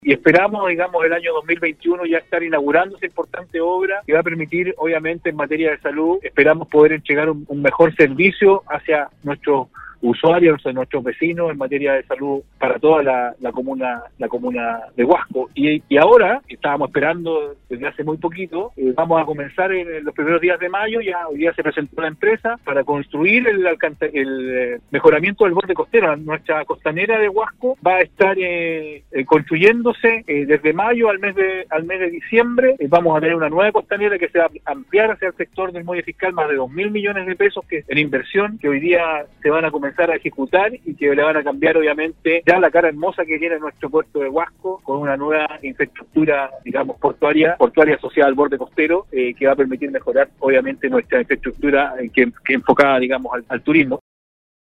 Este jueves la comuna de Huasco cumple 170 años, Nostálgica conversó con su alcalde Rodrigo Loyola quien destacó la trascendencia  de este importante hito llenó de historias y tradiciones, que han permitido el desarrollo de la comuna, que si bien es pequeña no está exenta de dificultades que con el pasar de los años han logrado ir superando.